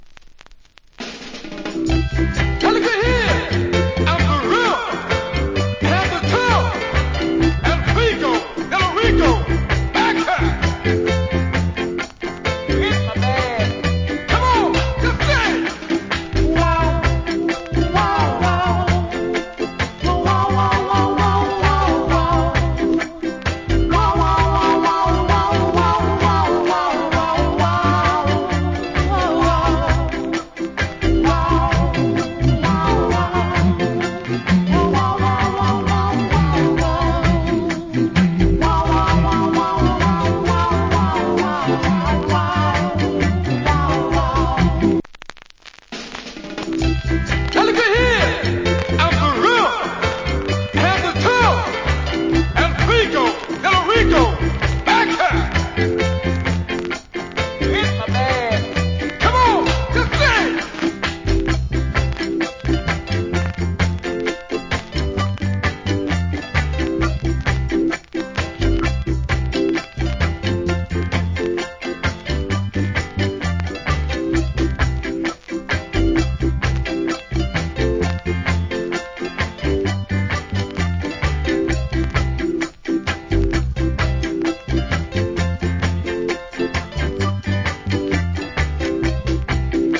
コメント Cool Early Reggae Vocal. / Good Early Reggae Inst.